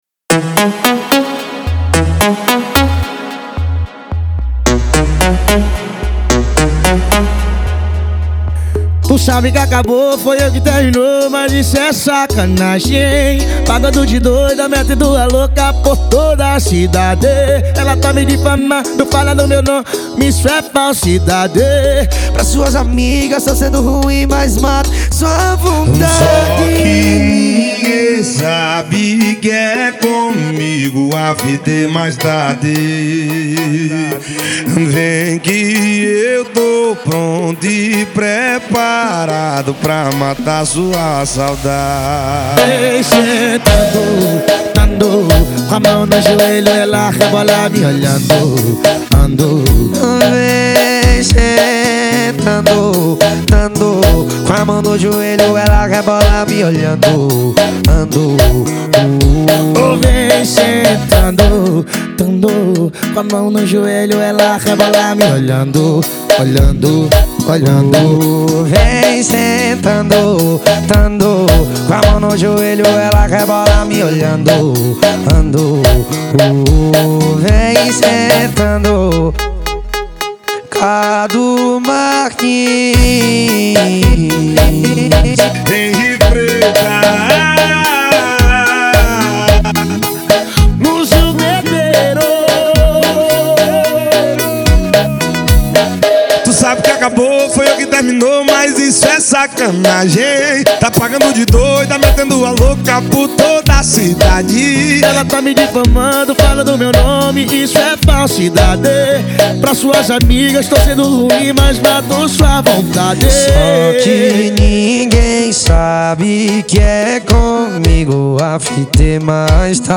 2025-02-14 18:04:35 Gênero: MPB Views